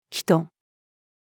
人-female.mp3